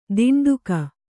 ♪ tinḍuka